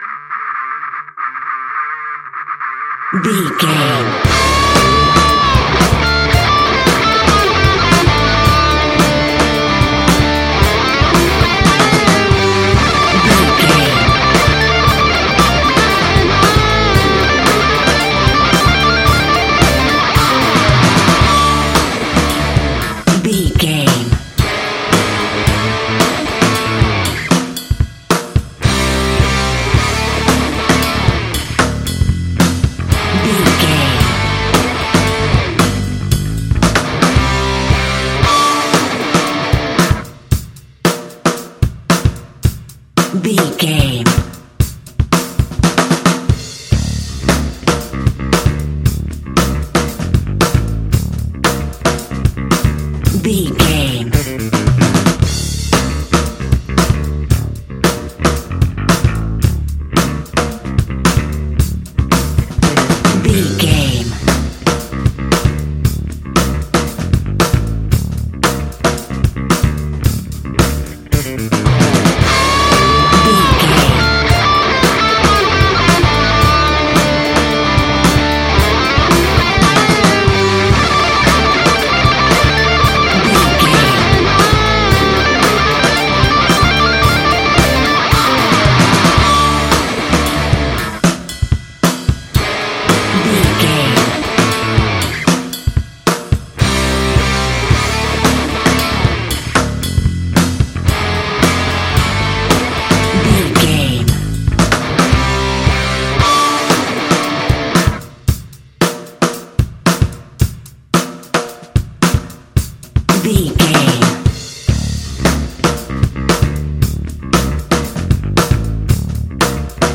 Ionian/Major
D
hard rock
heavy rock
distortion